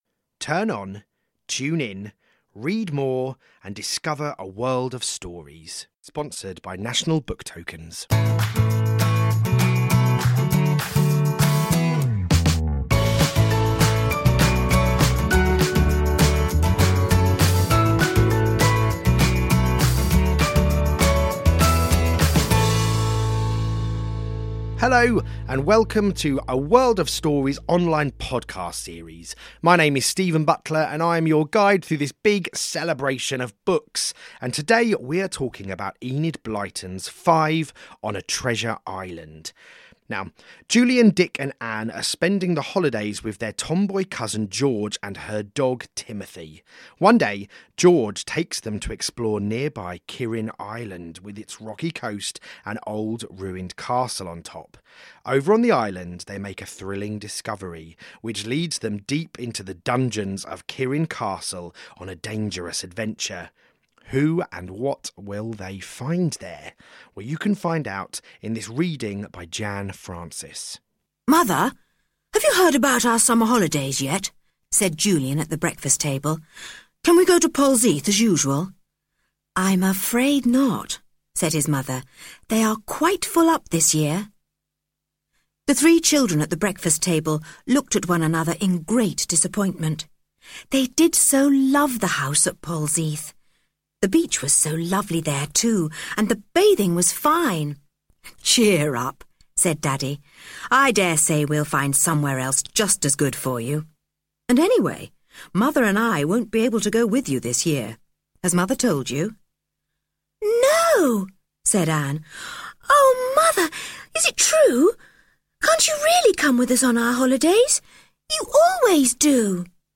Read by Jan Francis